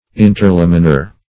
Search Result for " interlaminar" : The Collaborative International Dictionary of English v.0.48: Interlamellar \In`ter*lam"el*lar\, Interlaminar \In`ter*lam"i*nar\, a. (Anat.)
interlaminar.mp3